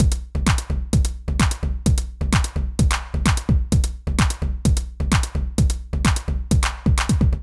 INT Beat - Mix 19.wav